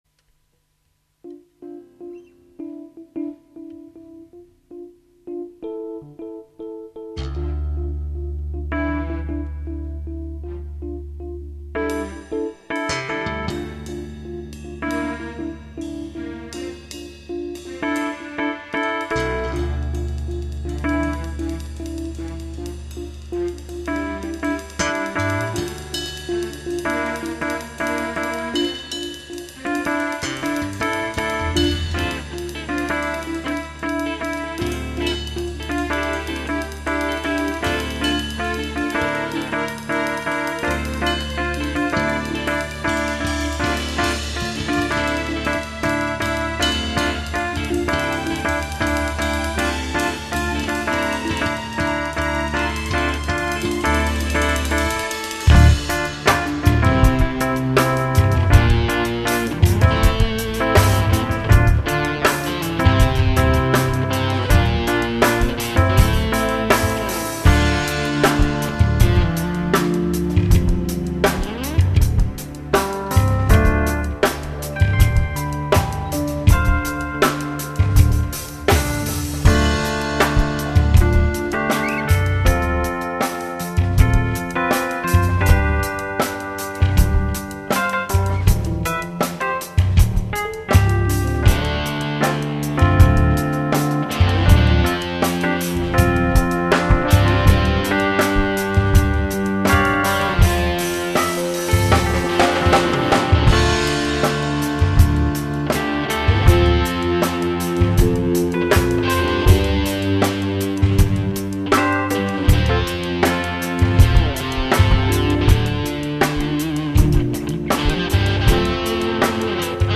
Wir haben hier einfach mal 2 mehr oder weniger spontane Einfälle zum Test unseres neuen Mini-Studio Equipment auf 2 Spuren aufgenommen.
Vor knapp 30 Jahren hat das die Ur-Band mal rockiger gespielt.
Bis auf ein paar kleine sphärische Highlights und die zu lauten Drums eher was zum nebenher hören.
Bei der ersten Nummer find ich den Groove geil, aber auf Dauer grooved sich's ein bissel tot.
zu 1: Geil, das Rockt!